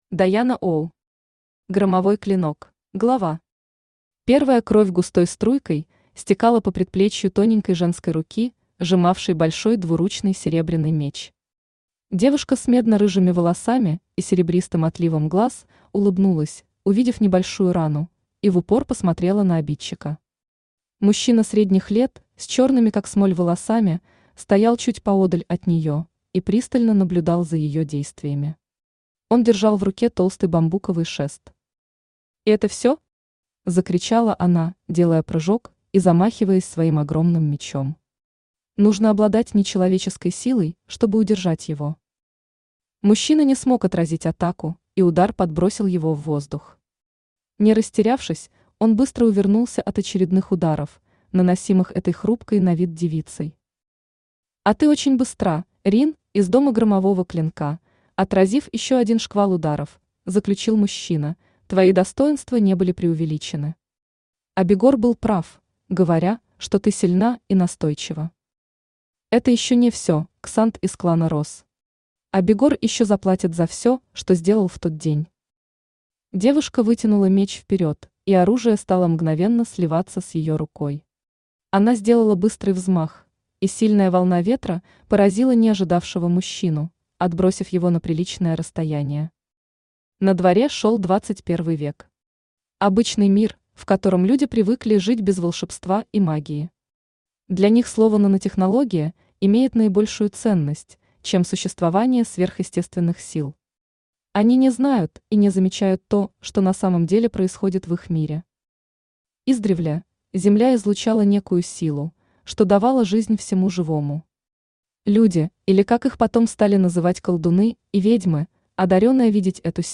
Аудиокнига Громовой Клинок | Библиотека аудиокниг
Aудиокнига Громовой Клинок Автор Diana Ow Читает аудиокнигу Авточтец ЛитРес.